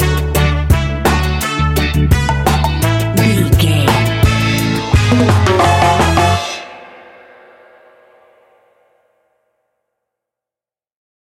Classic reggae music with that skank bounce reggae feeling.
Uplifting
Aeolian/Minor
F#
reggae
dub
laid back
chilled
off beat
skank guitar
hammond organ
transistor guitar
percussion
horns